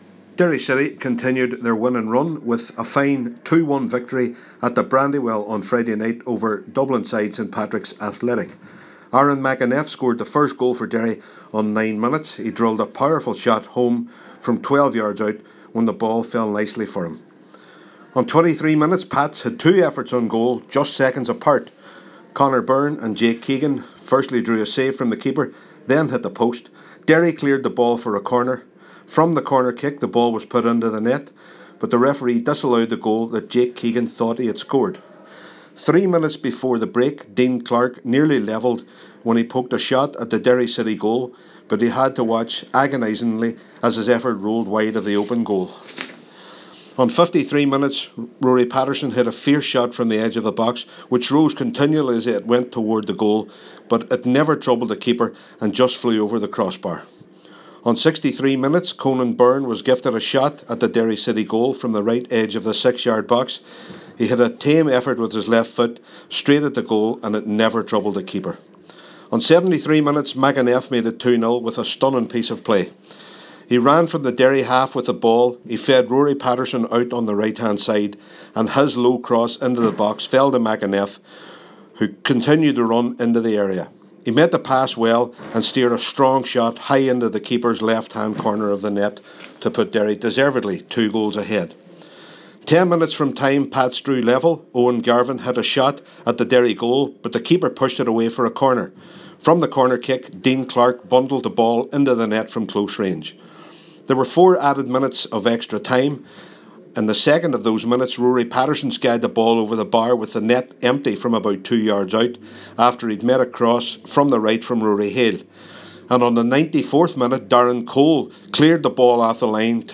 Derry City 2 St Pats 1 – FT Report